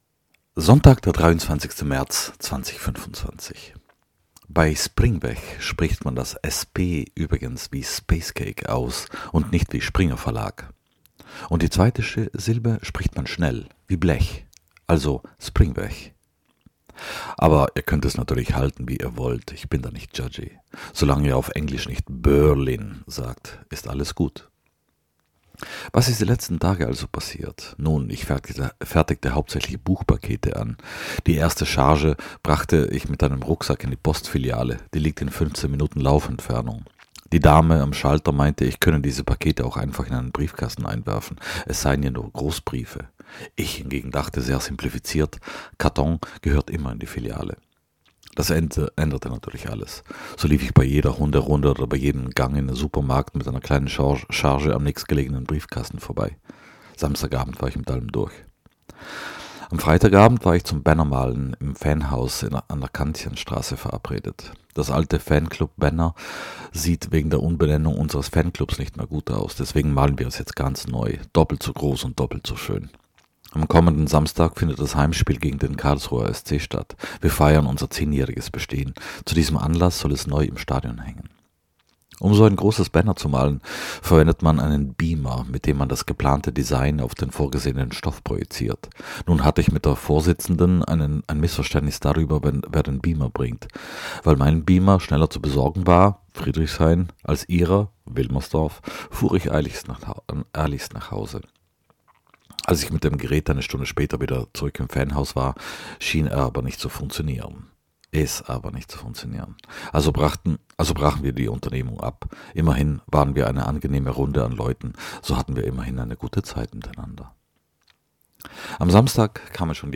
Bei „Springweg“ spricht man das „SP“ übrigens wie Spacecake aus und nicht wie Springerverlag. Und die zweite Silbe spricht man schnell.